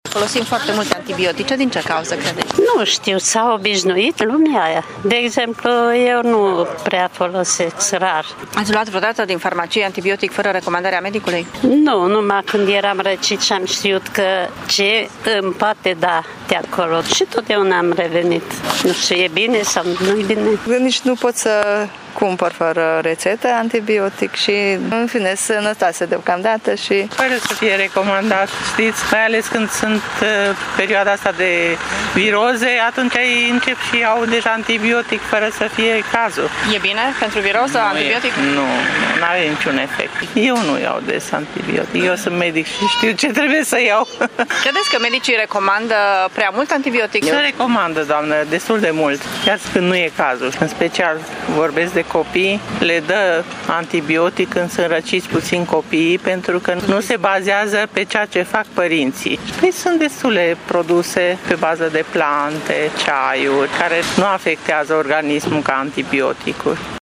Târgumureșenii recunosc că iau uneori antibiotice fără recomandare dar și medici care recomandă prea mult antibiotic la copii: